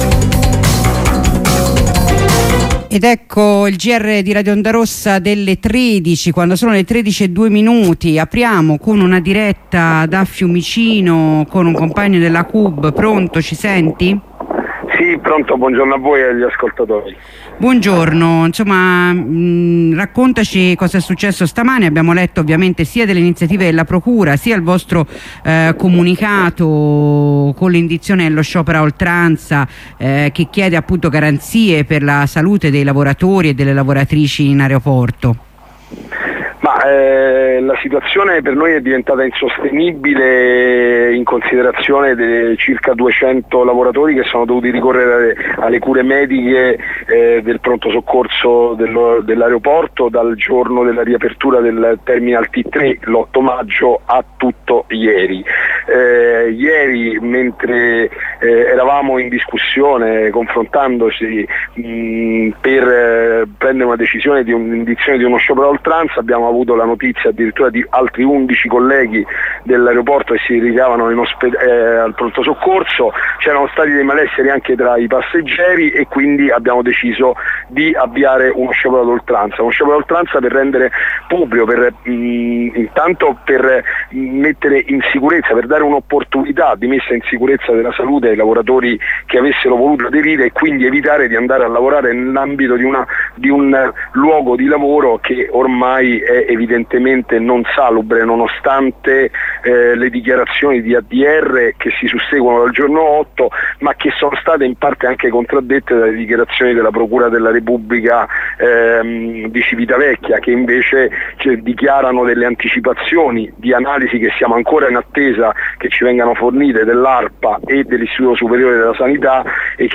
Corrispondenza con un compagno della CUB di Fiumicino.